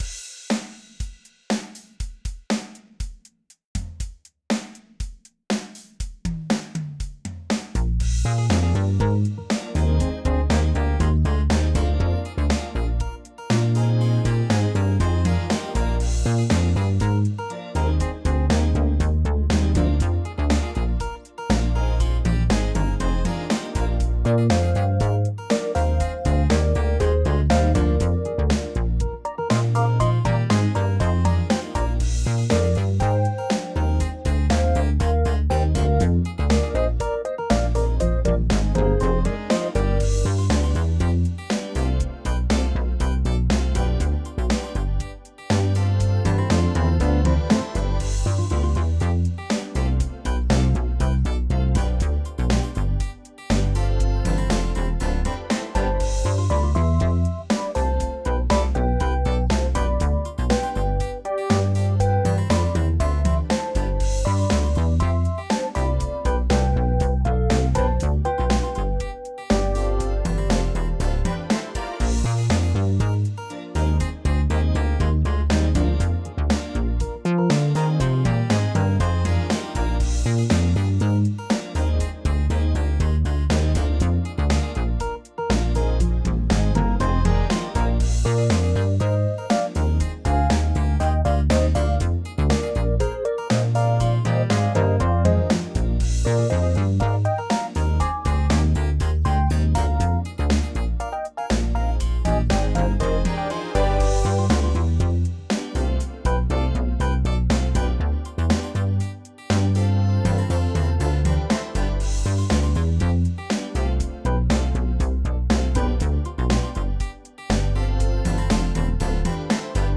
120bpm